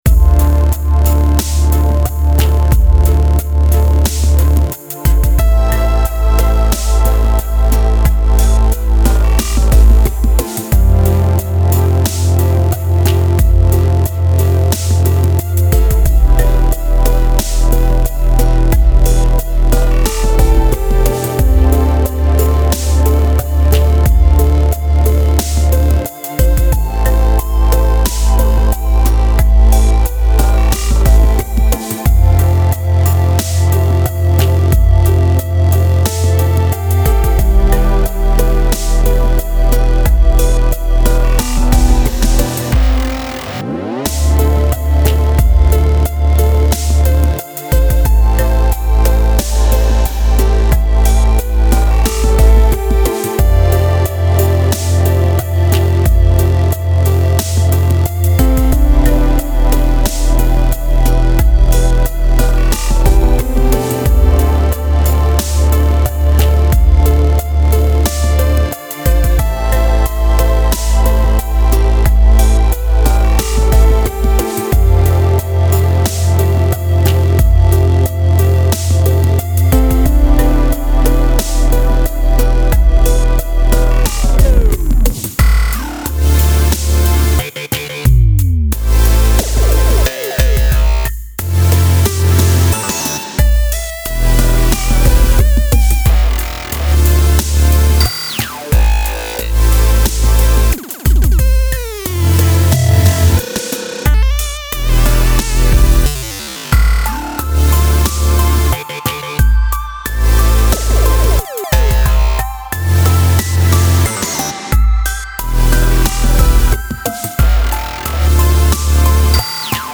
DubstepHip HopTrap
该产品融合了流行，颤音，Dubstep和Tra的声音，所有这些声音都经过精心设计，并包装在专业功能集内。
这些鼓组使用顶级的模拟鼓机录制，并具有胖模拟的踢腿，强大的小军鼓，强大的打击乐和帽子。